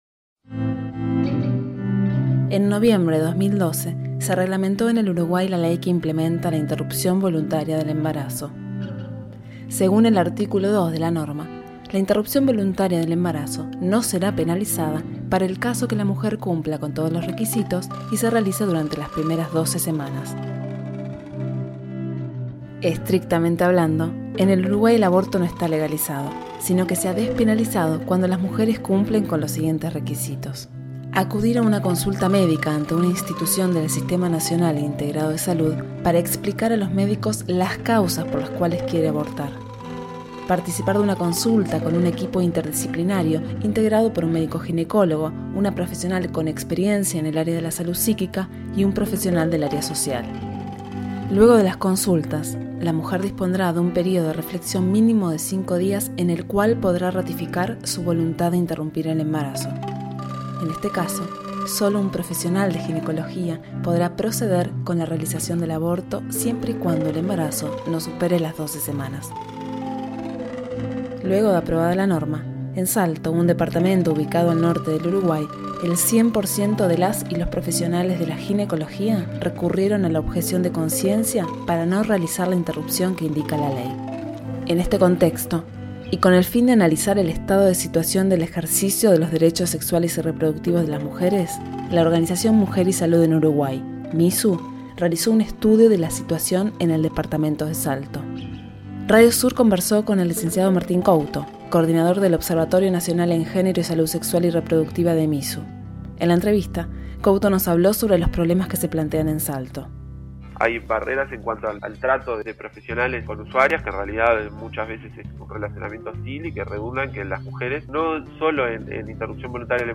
Aborto en Uruguay | Informe